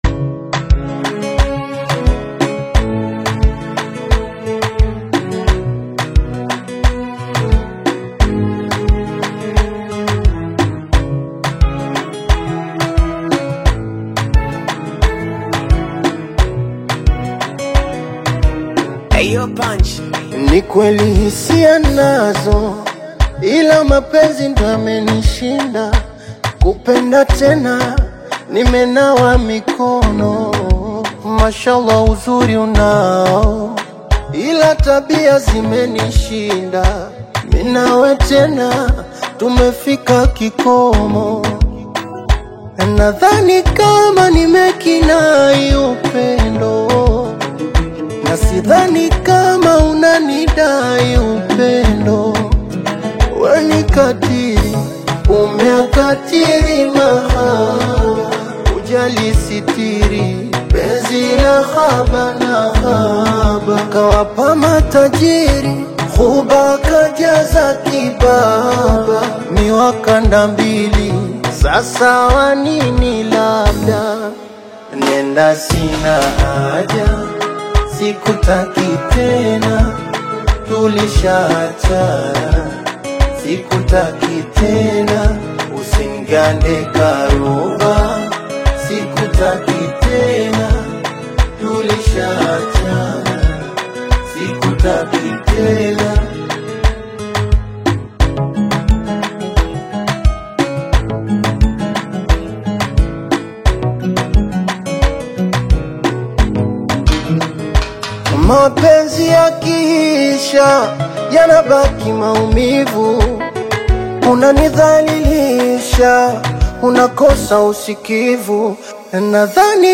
emotional Bongo Flava/Breakup anthem